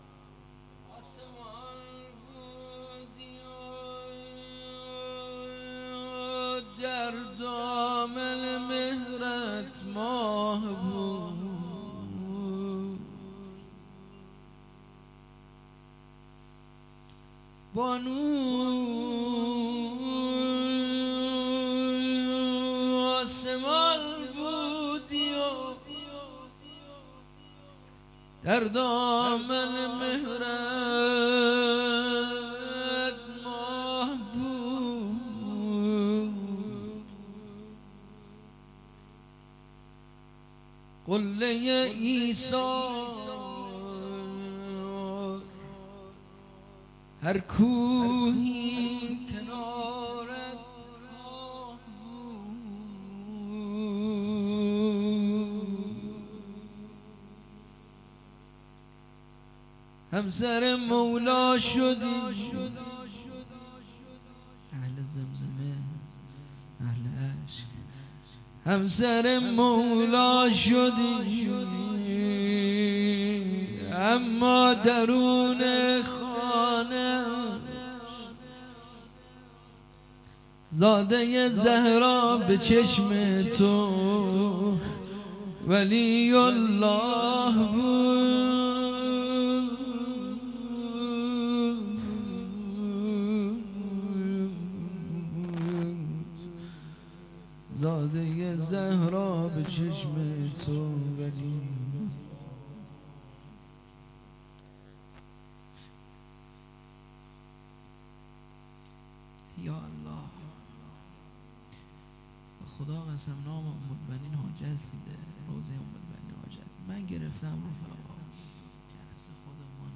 شب شهادت حضرت ام البنین علیه السلام
هیئت فرهنگی مذهبی نورالقرآن